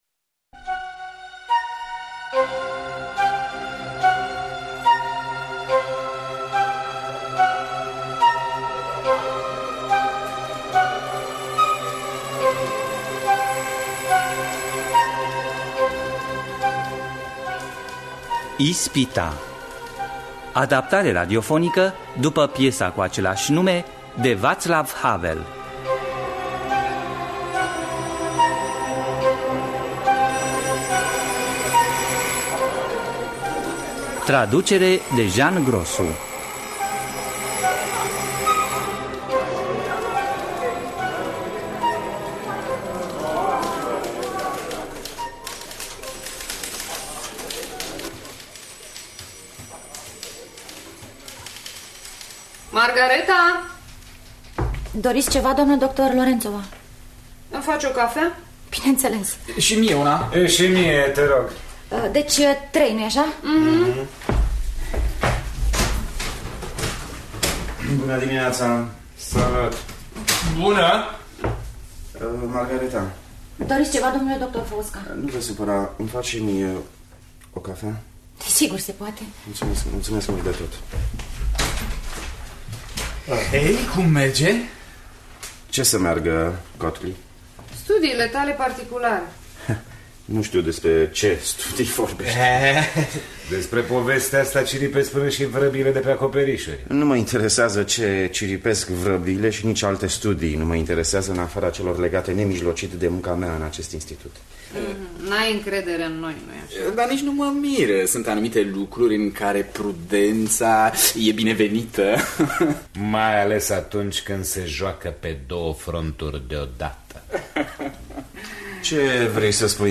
Ispita de Vaclav Havel – Teatru Radiofonic Online
adaptarea radiofonică